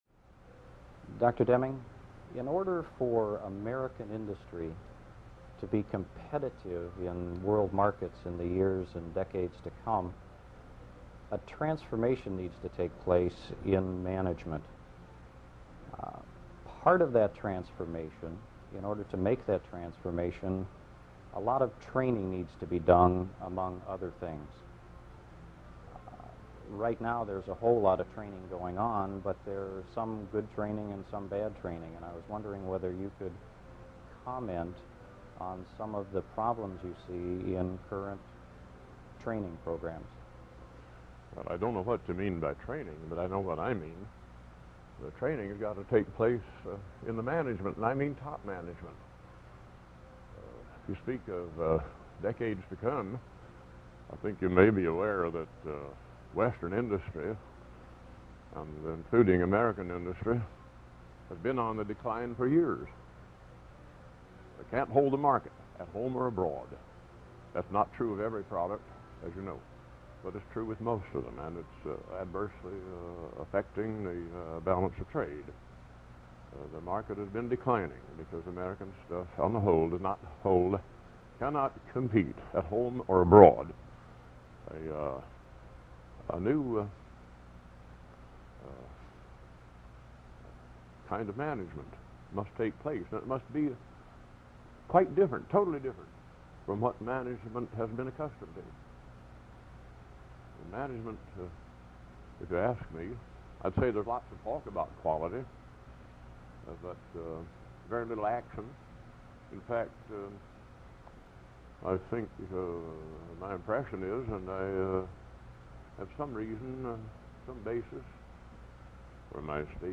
W Edwards Deming - Rare Full-Length Interview - February 1984